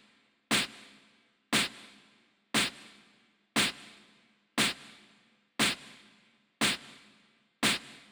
12 Clap.wav